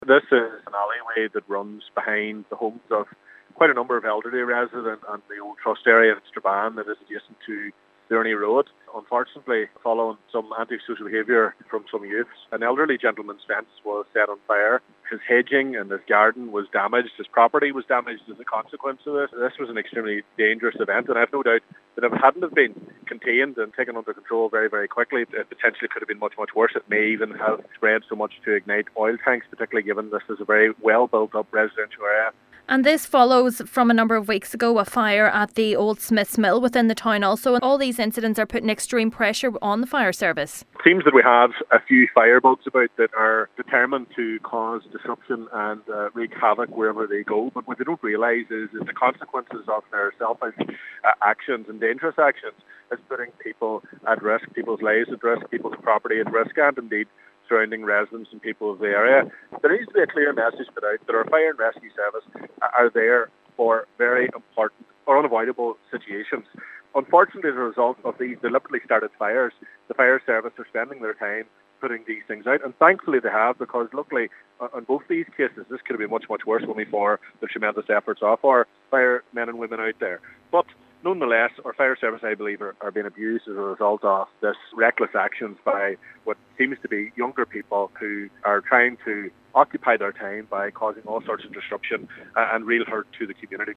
Local MLA Daniel McCrossan says as a result of the recent events, undue pressure is being put on the fire service: